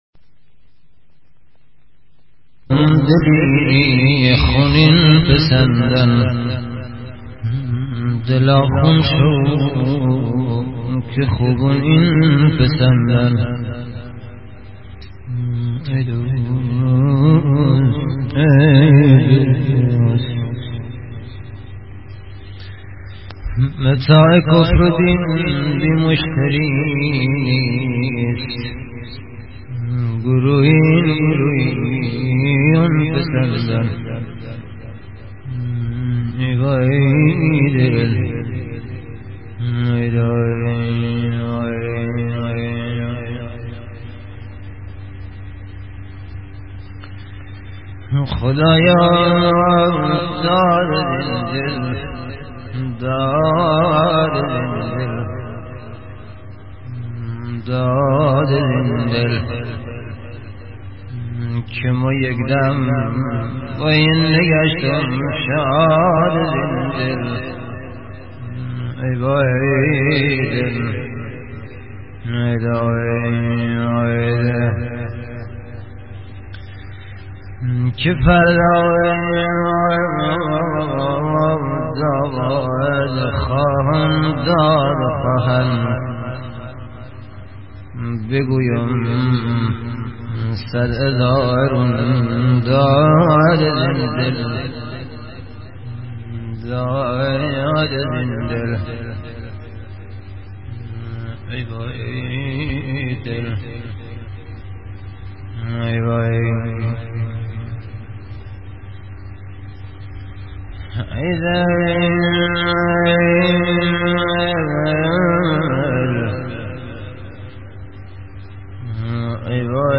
سبک اصیل دشتی سوزناک و غمگین
استاد شروه خوان اهل شهرستان دشتی و خورموج